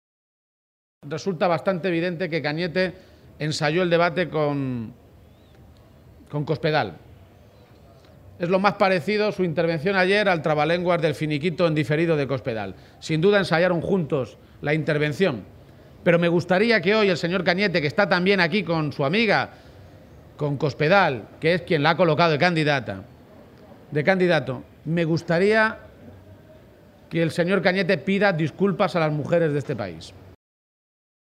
García-Page se pronunciaba de esta manera esta mañana en una comparecencia ante los medios de comunicación, durante su visita a las Ferias de Mayo de Talavera.
Cortes de audio de la rueda de prensa